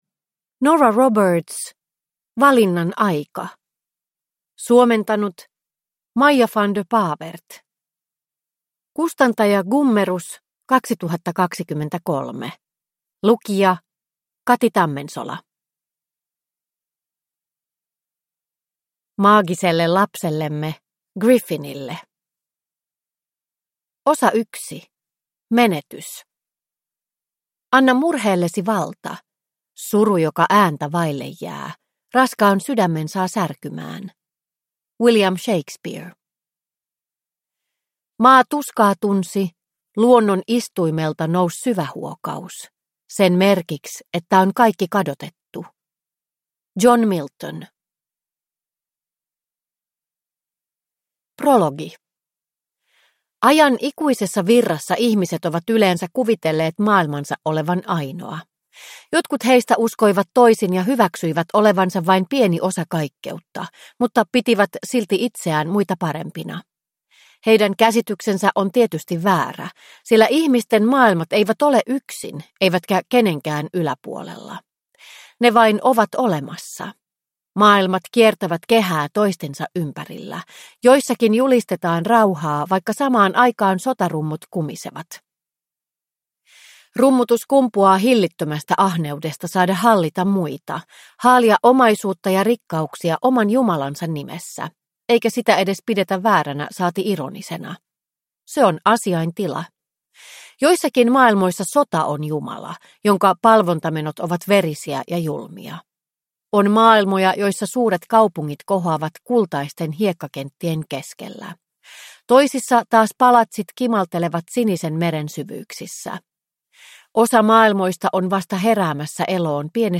Valinnan aika (ljudbok) av Nora Roberts